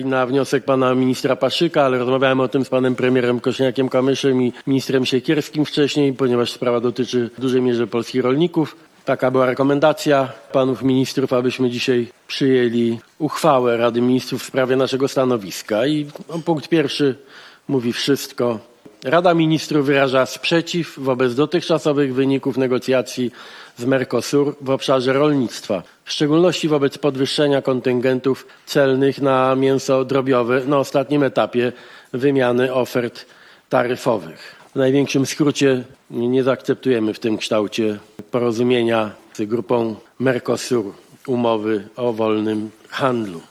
Polska nie zaakceptuje umowy o wolnym handlu z krajami Ameryki Południowej. Kształt porozumienia z Unią Europejską, a Brazylią, Argentyną, Urugwajem, Paragwajem jest nie do przyjęcia – poinformował dzisiaj premier Donald Tusk, tuż przed posiedzeniem Rady Ministrów